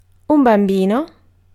Ääntäminen
US : IPA : [bɔɪ]